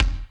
Index of /90_sSampleCDs/Best Service Dance Mega Drums/BD HIP 02 B